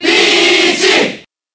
Peach_Cheer_Japanese_SSBB.ogg